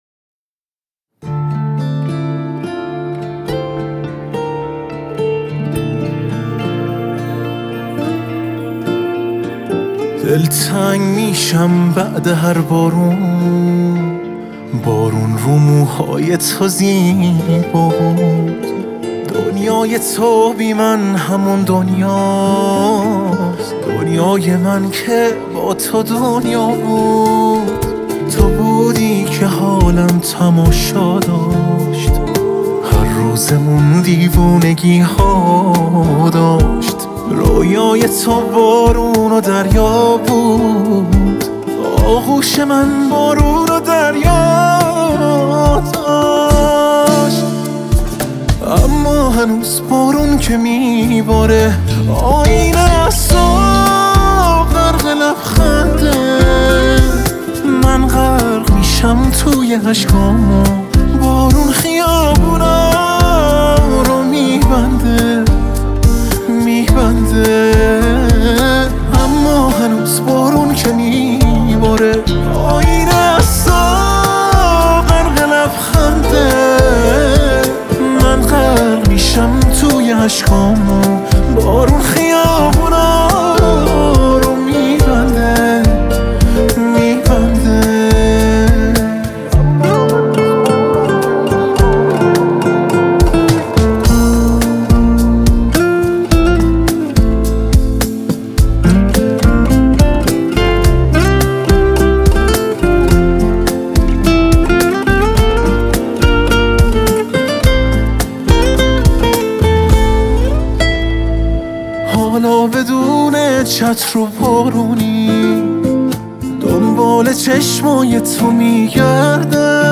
آهنگ احساسی